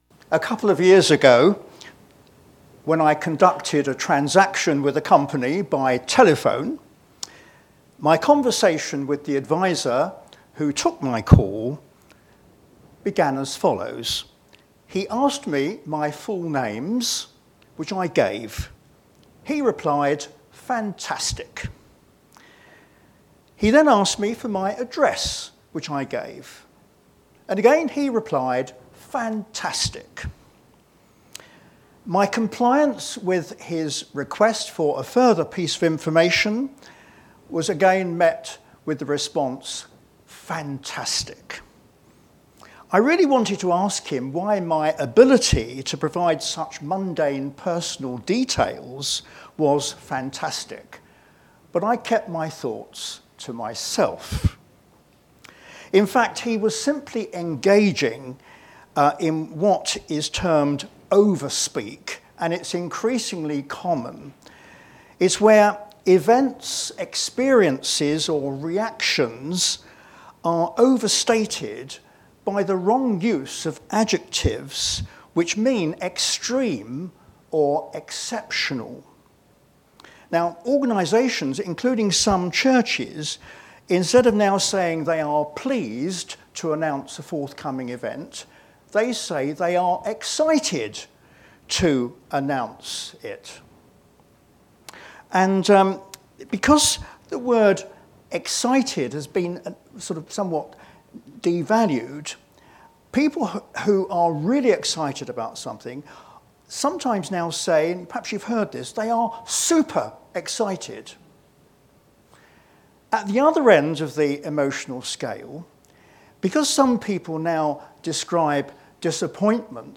Media for p.m. Service on Sun 15th Dec 2024 15:30
Theme: Sermon